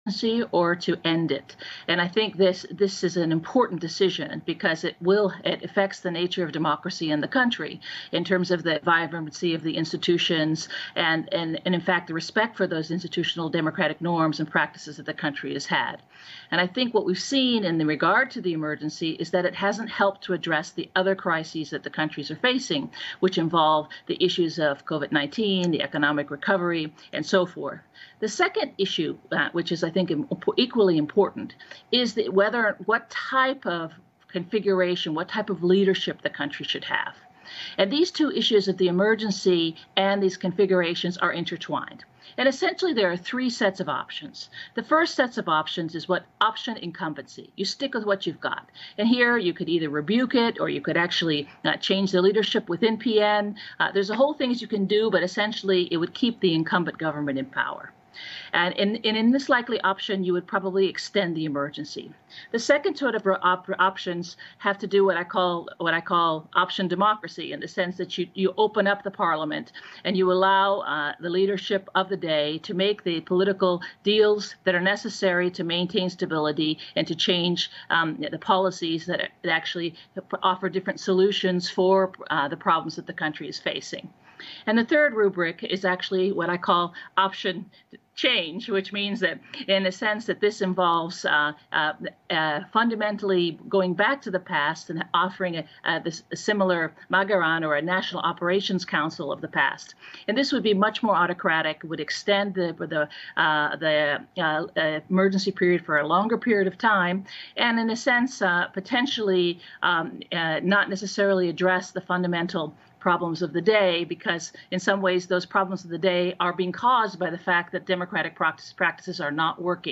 ask political analysts about current political dynamics